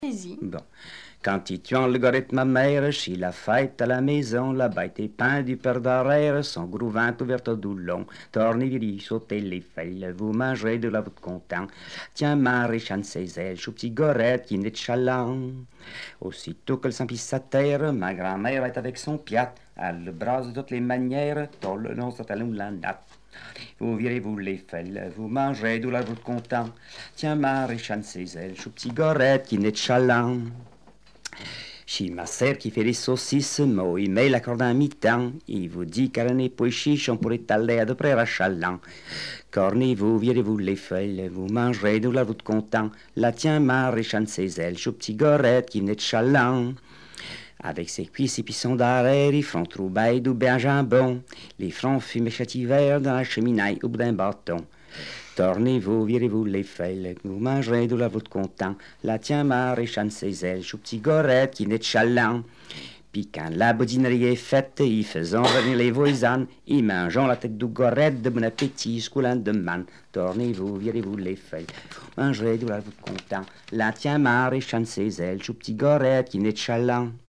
Genre strophique
Témoignages et chansons populaires et traditionnelles
Pièce musicale inédite